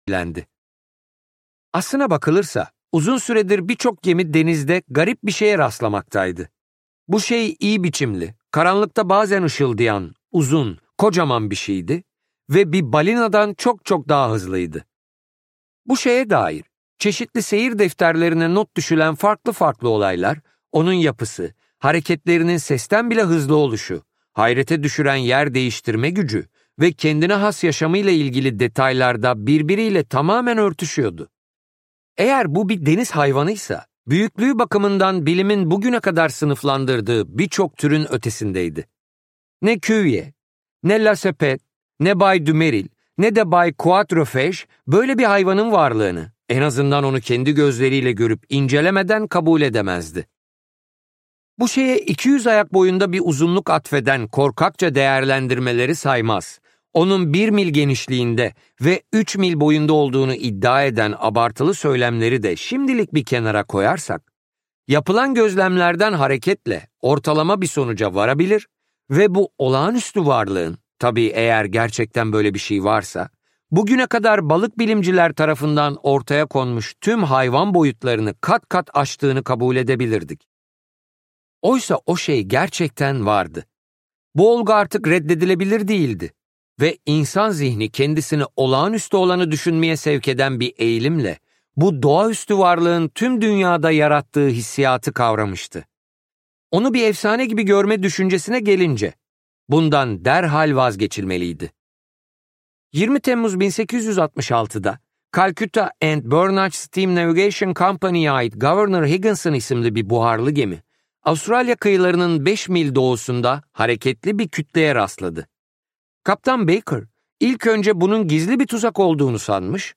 Seslendiren